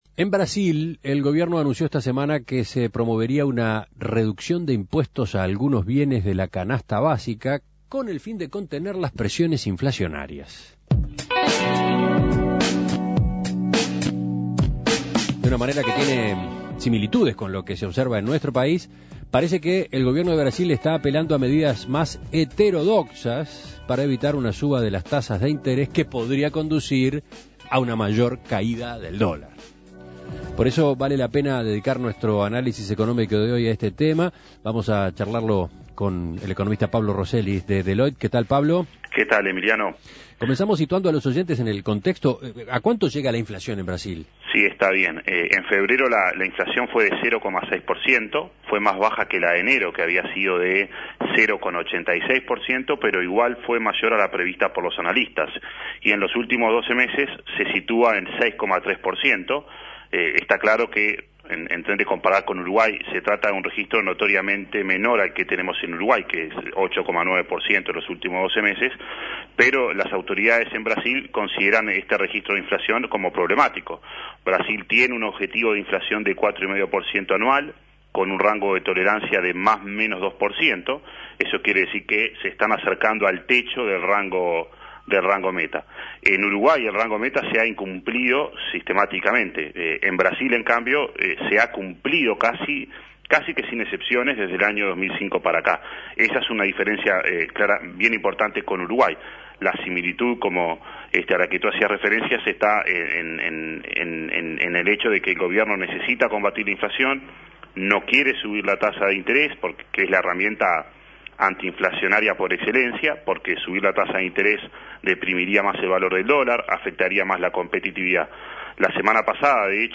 Análisis Económico En Brasil la inflación no cede y el Gobierno apela a rebajas de impuestos y tarifas.